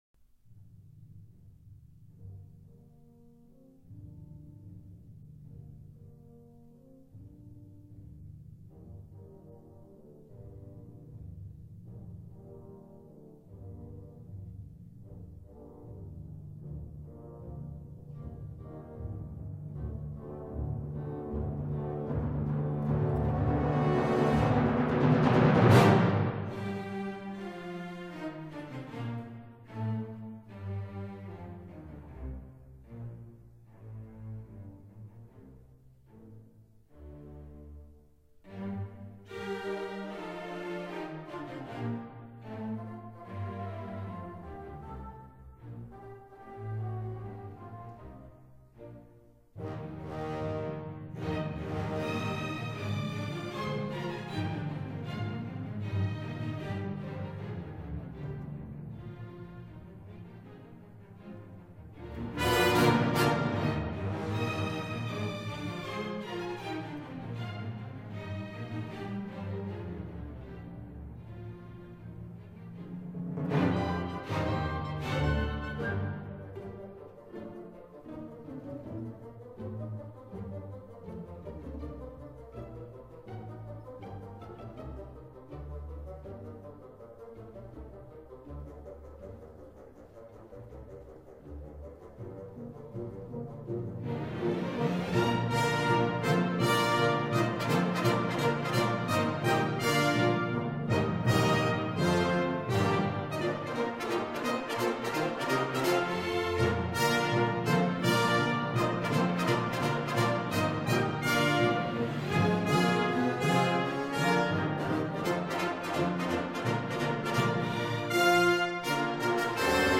●制式：STEREO DDD